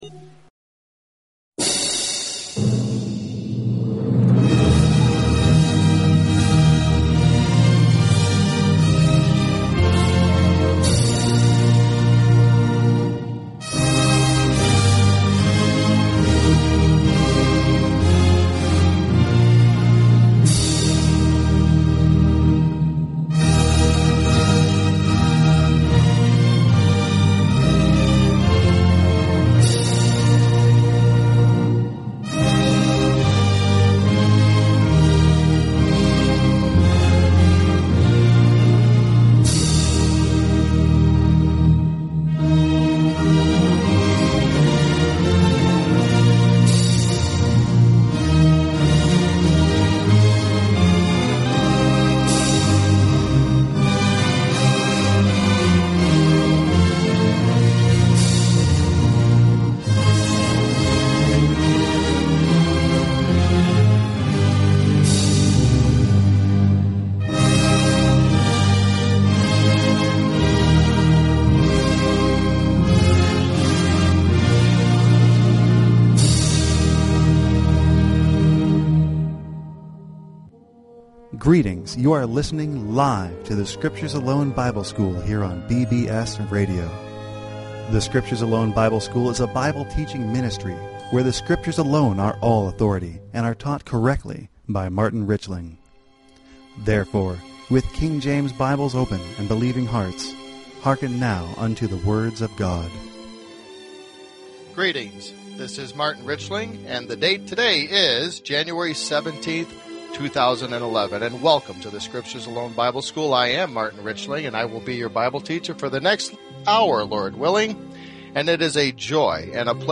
Talk Show Episode, Audio Podcast, The_Scriptures_Alone_Bible_School and Courtesy of BBS Radio on , show guests , about , categorized as